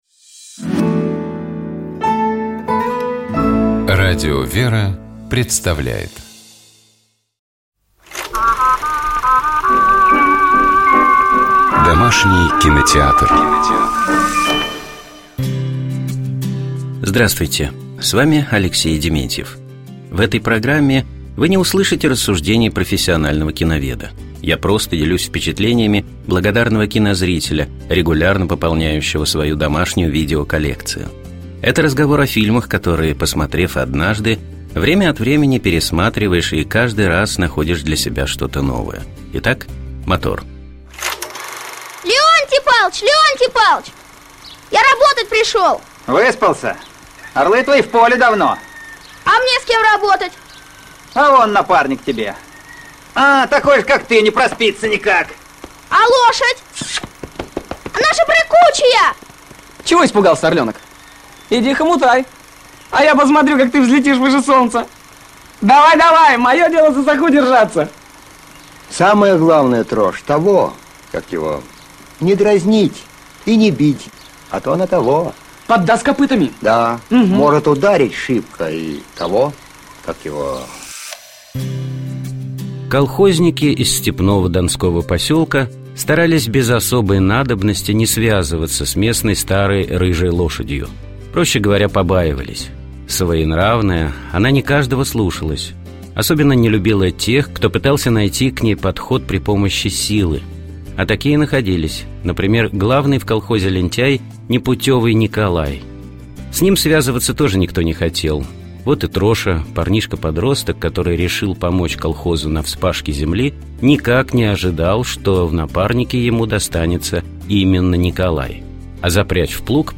Фрагмент из него прозвучал в начале программы.